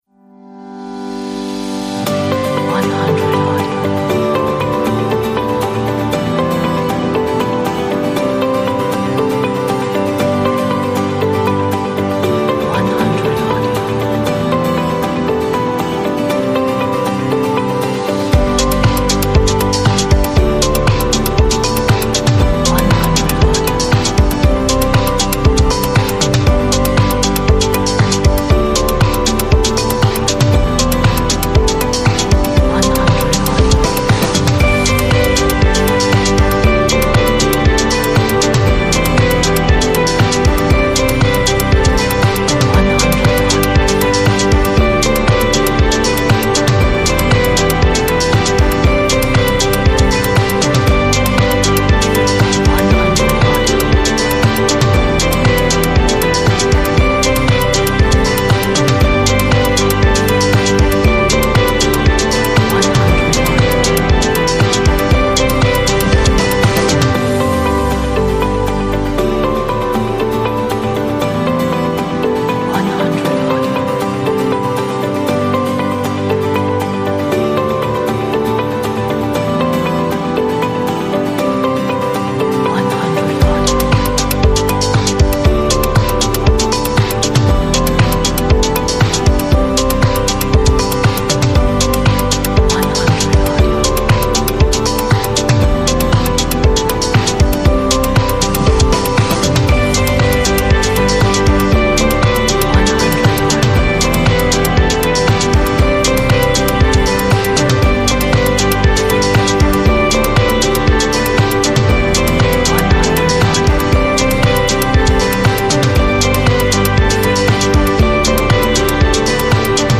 a nice pop upbeat 这是一首适合Vlog，媒体视频，广告等项目的流行音乐。